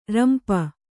♪ rampa